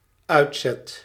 Ääntäminen
IPA: /ˈœy̯tˌsɛt/